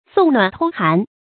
送暖偷寒 注音： ㄙㄨㄙˋ ㄋㄨㄢˇ ㄊㄡ ㄏㄢˊ 讀音讀法： 意思解釋： 見「送暖偷寒」。